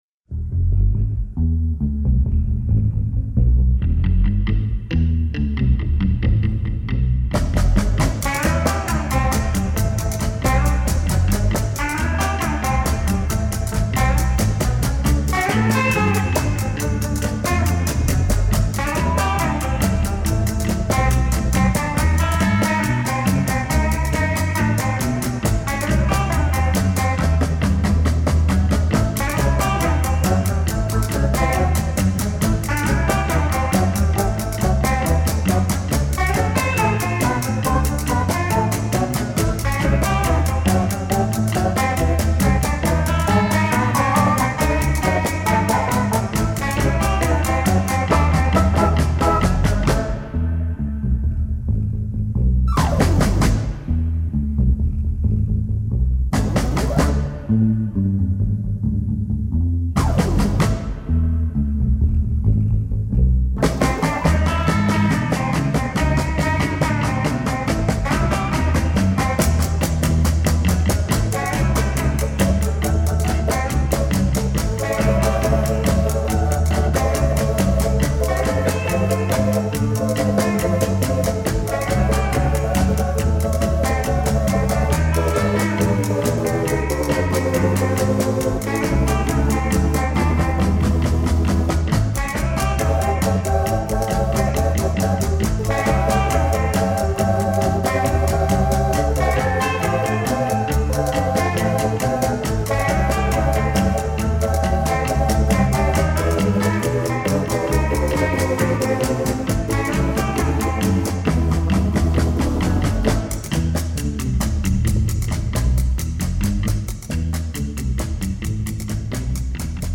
Обожаю surf-music.